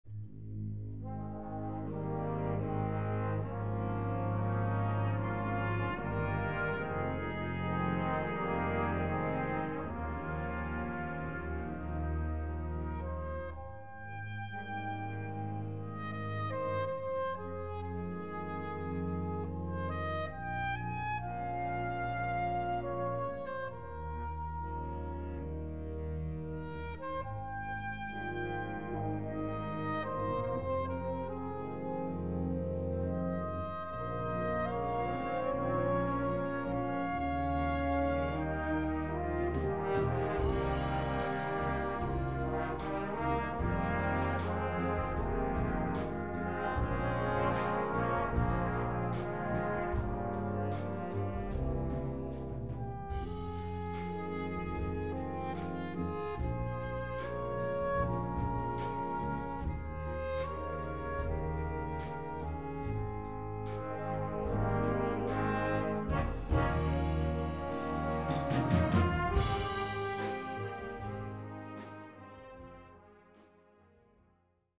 Répertoire pour Harmonie/fanfare - Trompette et Fanfare